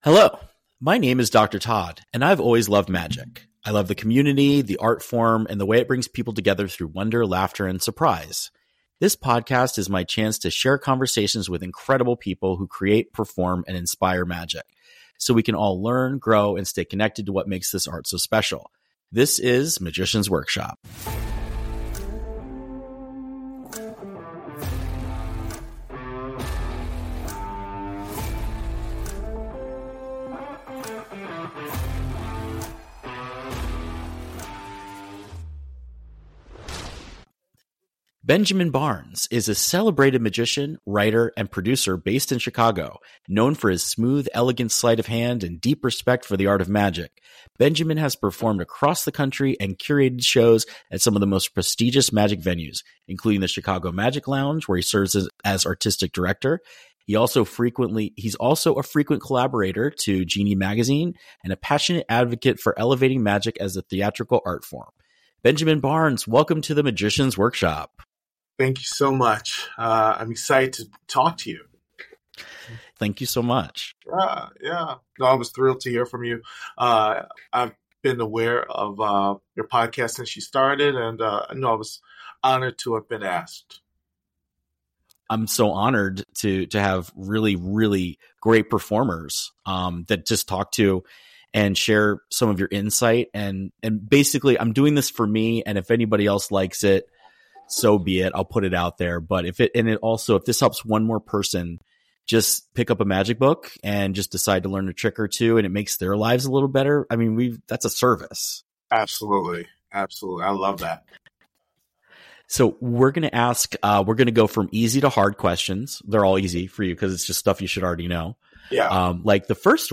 This is an interview for the record books; this guest is an absolute legend.